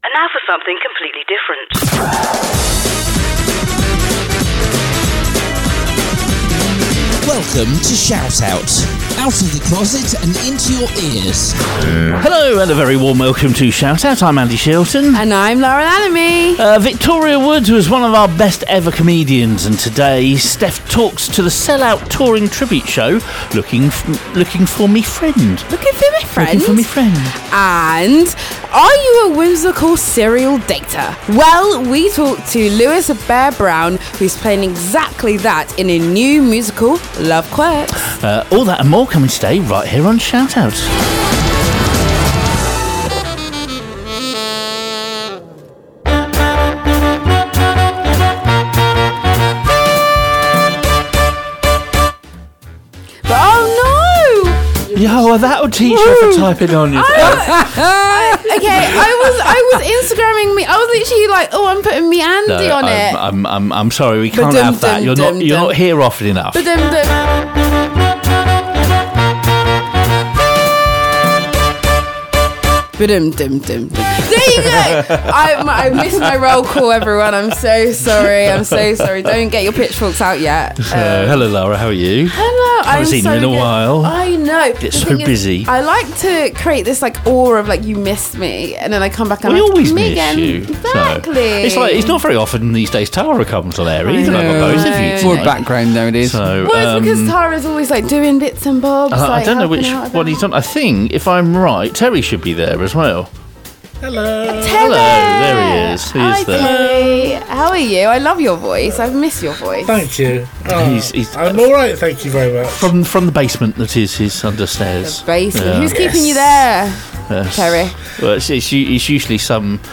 Broadcasting a brand new show every week, ShoutOut is a magazine show aimed at the LGBT community and their friends. We cover everything from serious to the stupid with live guests, events and news listing and special features.